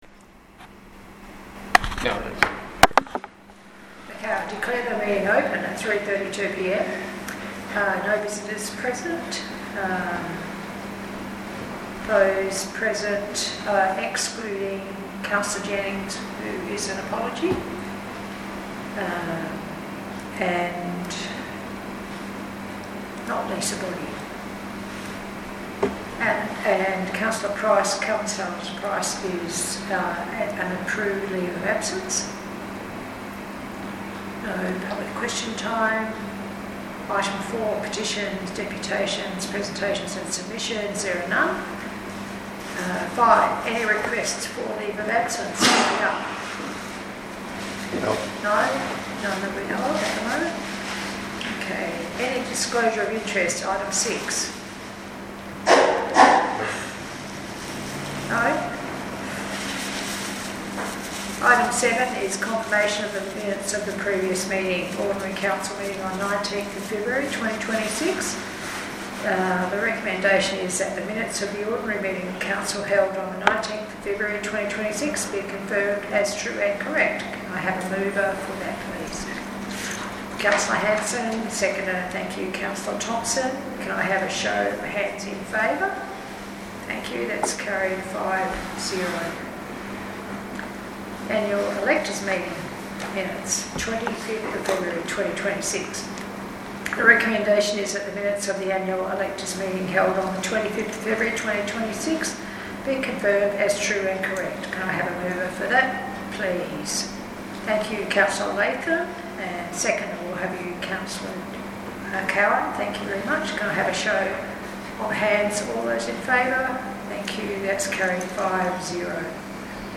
march-2026-ocm-recording.mp3